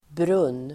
Uttal: [brun:]